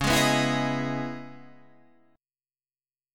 Dbm9 chord